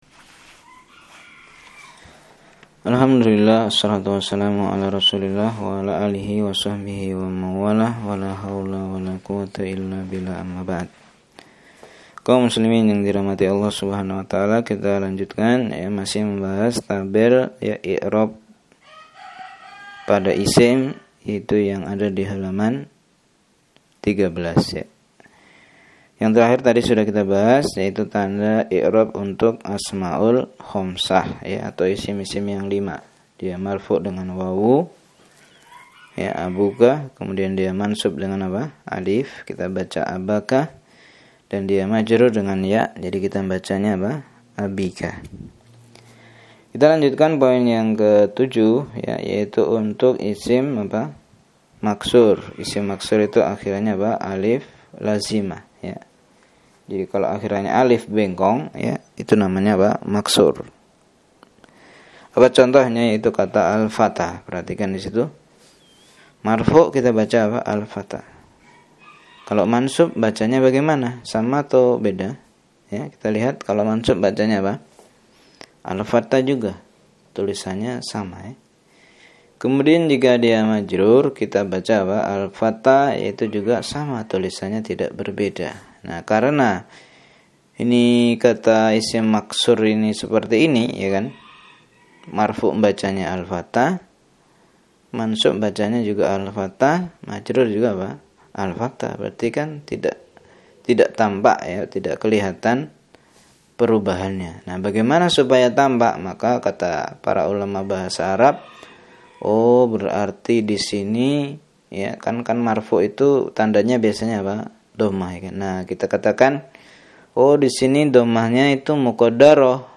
Rekaman Pelajaran Muyassar